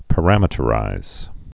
(pə-rămĭ-tə-rīz) also pa·ram·e·trize (-ĭ-trīz)